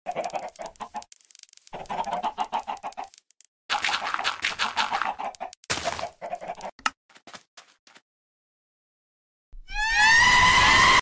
Minecraft chicken
Minecraft-chicken.mp3